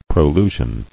[pro·lu·sion]